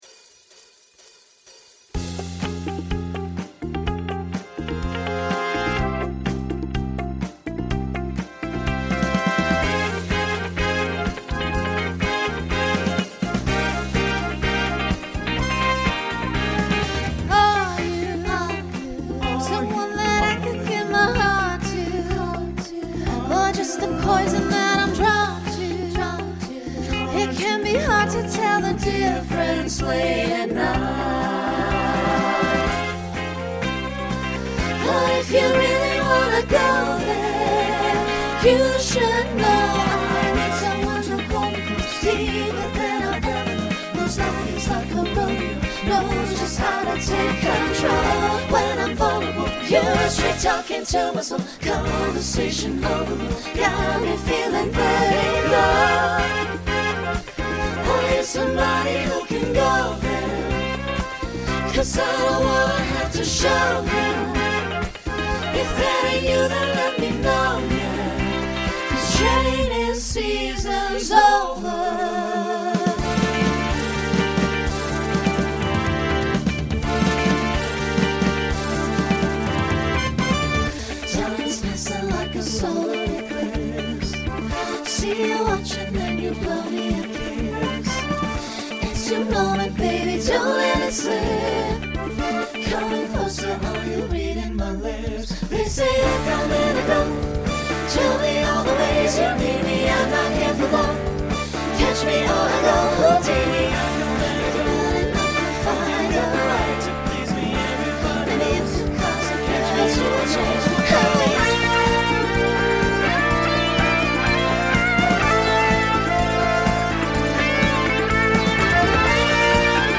Pop/Dance Instrumental combo
Voicing SATB